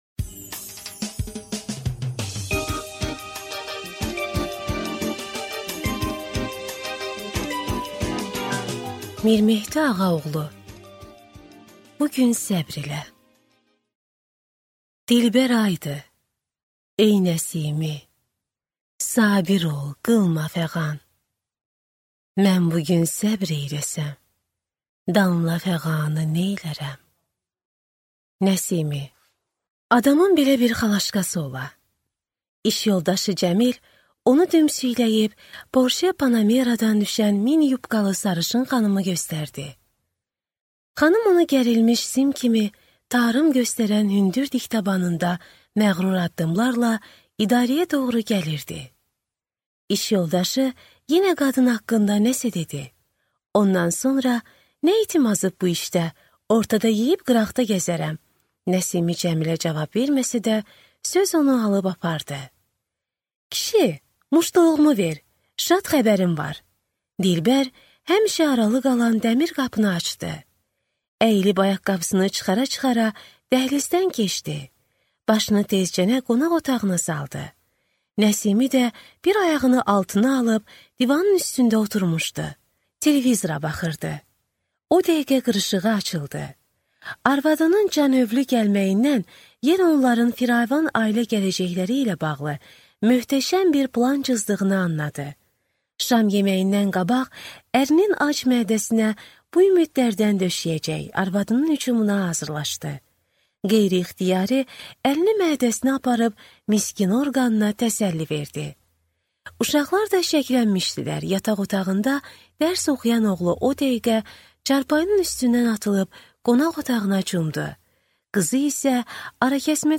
Аудиокнига Bu gün səbr elə | Библиотека аудиокниг